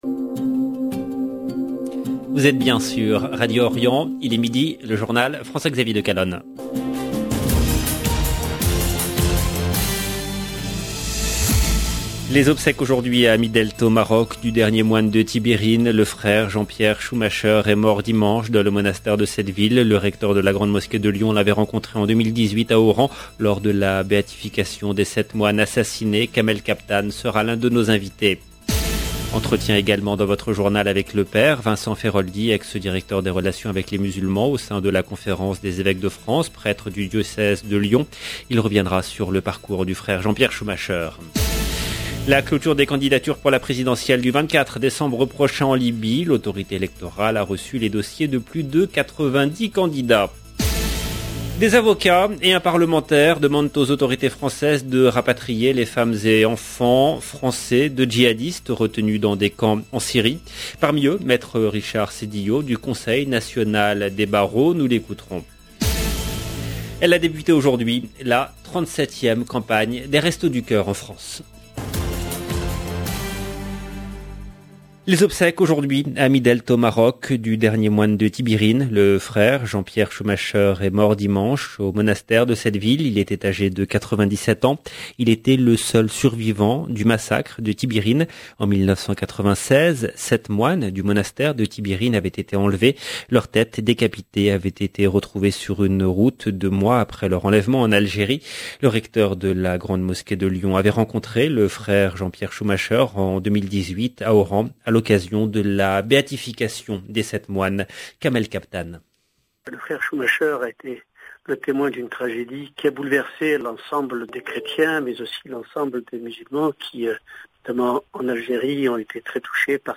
Islam Algerie Libye 23 novembre 2021 - 18 min 13 sec LE JOURNAL DE MIDI EN LANGUE FRANCAISE DU 23/11/21 LB JOURNAL EN LANGUE FRANÇAISE Les obsèques aujourd’hui à Midelt au Maroc du dernier moine de Tibhirine.